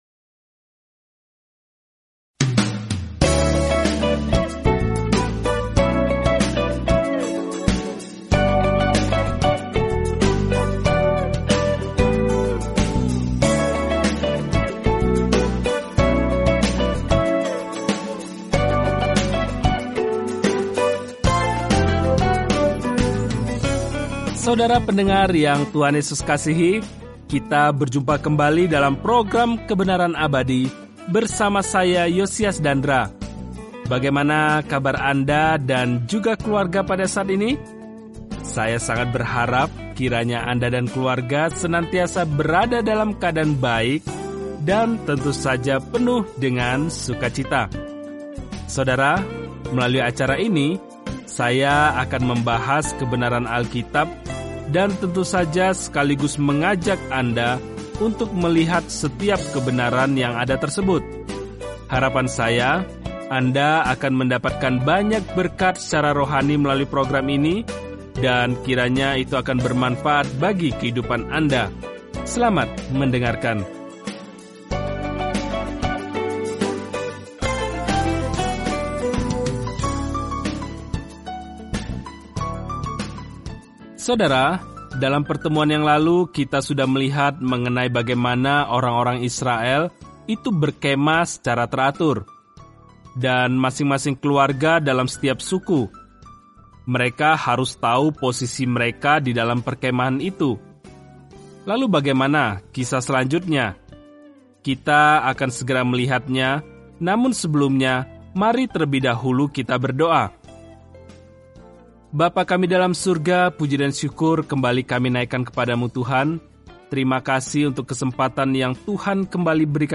Firman Tuhan, Alkitab Bilangan 3 Bilangan 4 Hari 2 Mulai Rencana ini Hari 4 Tentang Rencana ini Dalam kitab Bilangan, kita berjalan, mengembara, dan beribadah bersama Israel selama 40 tahun di padang gurun. Jelajahi Numbers setiap hari sambil mendengarkan studi audio dan membaca ayat-ayat tertentu dari firman Tuhan.